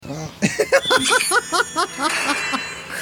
Download Funny Laugh Meme sound effect for free.
Funny Laugh Meme